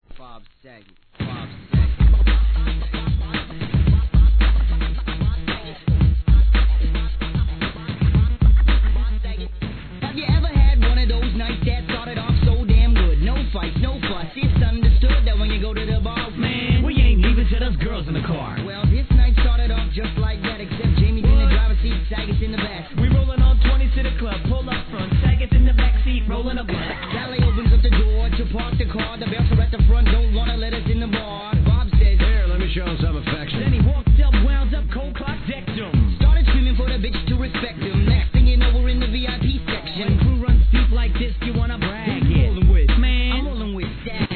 HIP HOP/R&B
エレクトリックなOld Schoolライクなトラックのアッパーパーティチューン!!